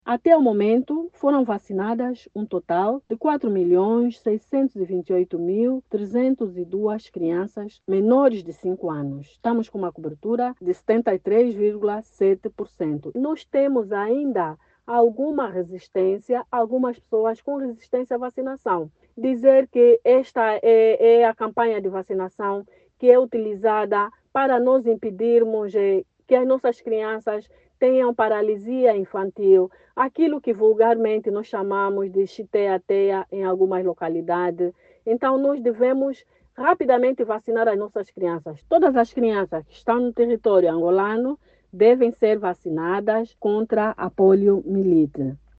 Em entrevista a RNA